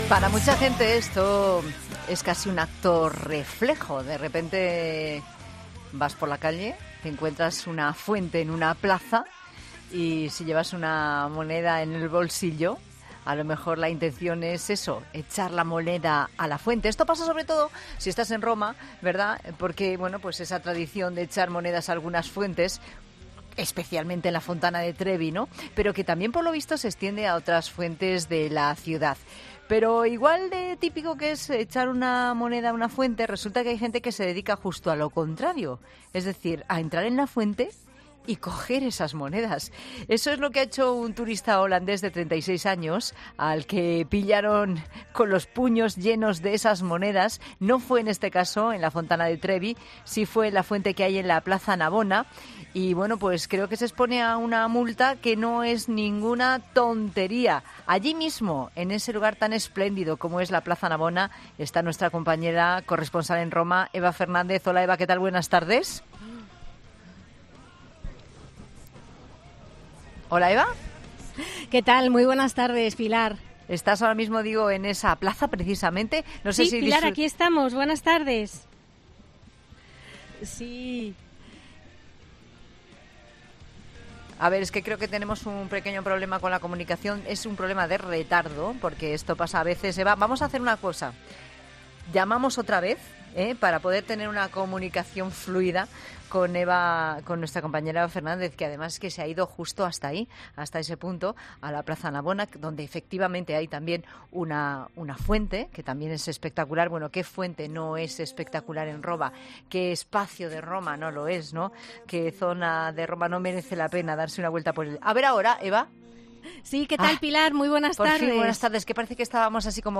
''No suelo tirar monedas a las fuentes, no me creo esas cosas''. contaba la turista en La Tarde en Cope.